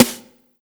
84 SNARE  -R.wav